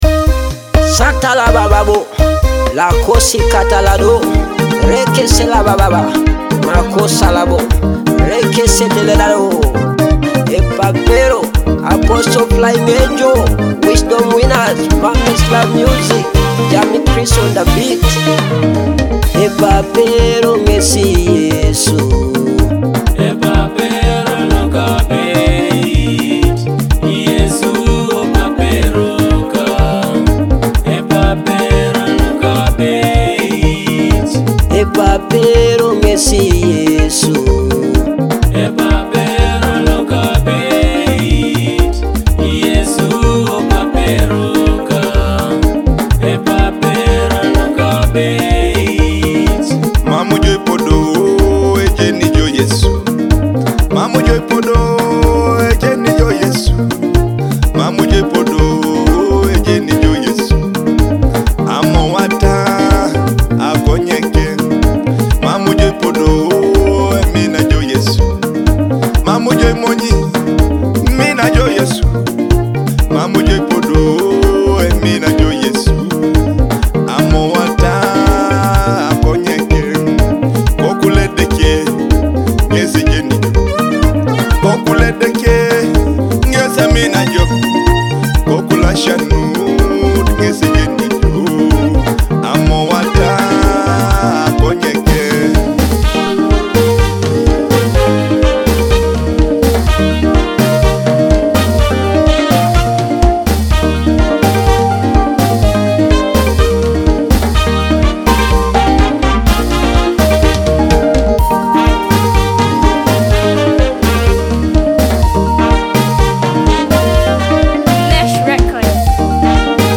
gospel hit